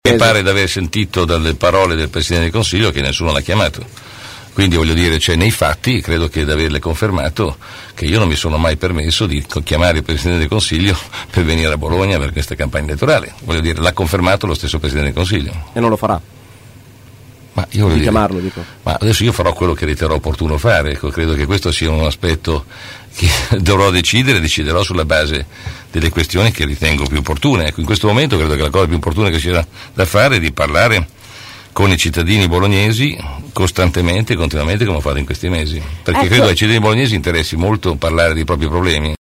ai nostri microfoni, durante la trasmissione “Angolo B”.